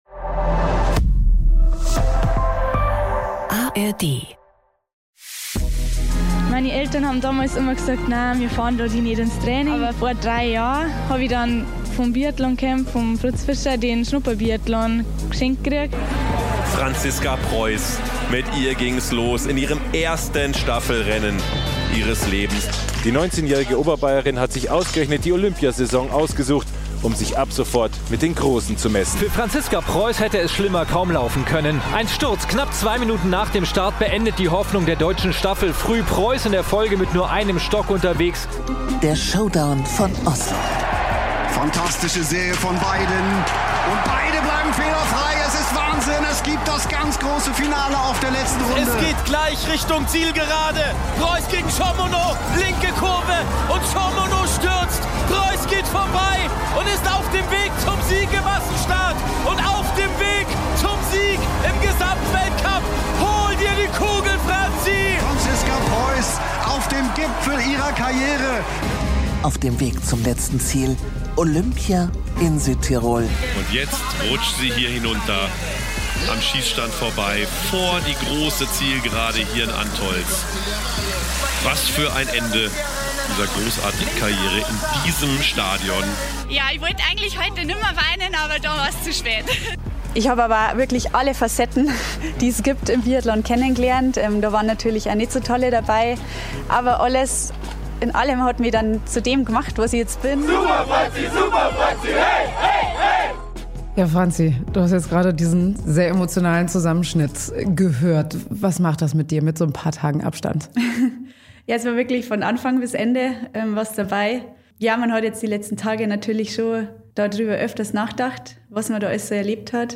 Kurz danach treffen wir sie in Ruhpolding, in der Chiemgau Arena, ihrem sportlichen Wohnzimmer. Im Wintersport-Podcast spricht Preuß über die ereignisreichen Tage rund um ihre letzten Rennen und darüber, wie sie ihr Karriereende geplant hat.
Auch der ehemalige Skirennläufer und jetzige Trainer Thomas Dreßen kommt zu Wort: Er spricht offen über das Loch, in das er nach seinem Karriereende gefallen ist - und darüber, was Athletinnen und Athleten in dieser Übergangsphase erwartet.